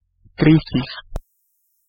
Ääntäminen
IPA : /əˈtæk/